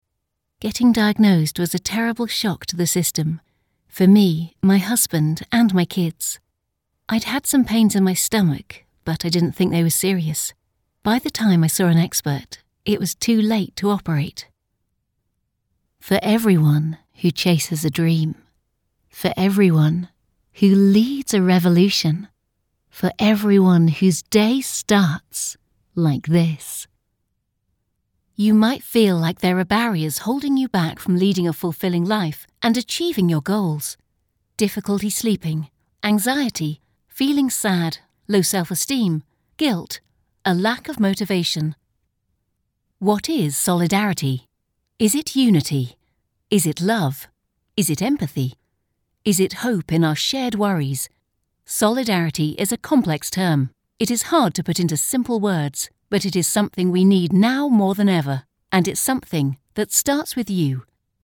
I have my own studio where I record and edit to the highest professional standards, offering live direction where required.
Rode NT-1A microphone
Young Adult
Middle-Aged
Mezzo-SopranoSoprano